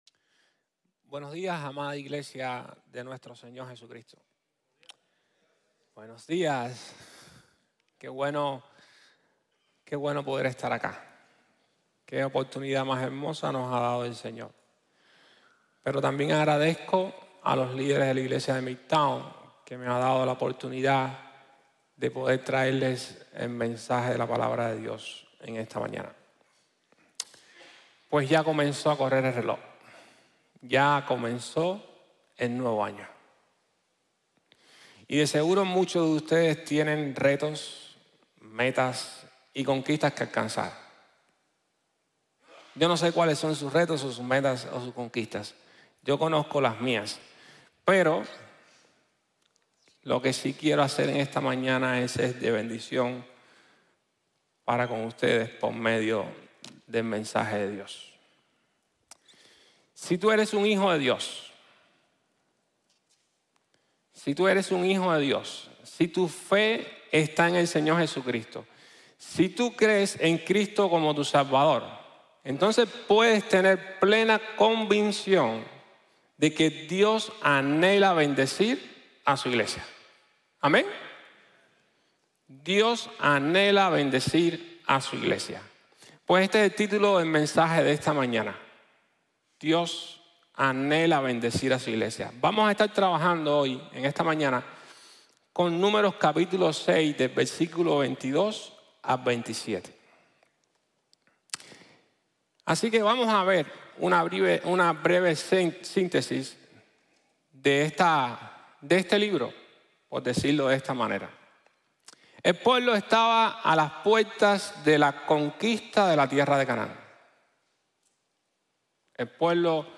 Dios ANHELA bendecir a Su iglesia | Sermón | Grace Bible Church